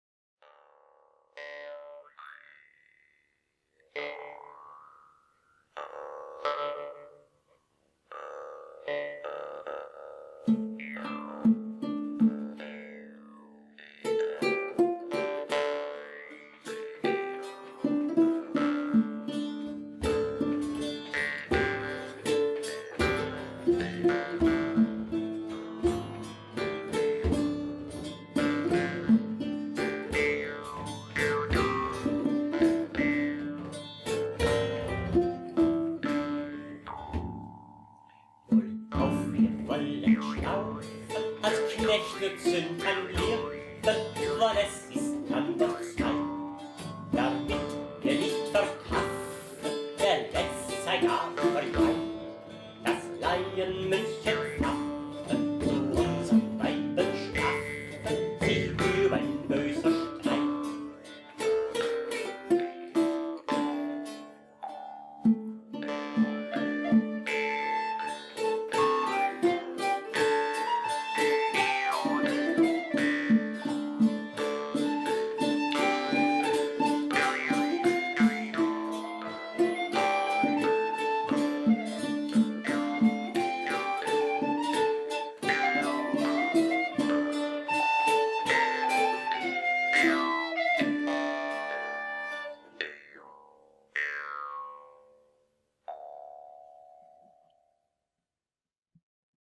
Maultrommeln
Track 2, Typ Horsens: Wohlauf, wir wollen schlafen (Oswald von Wolkenstein, 15.Jh.)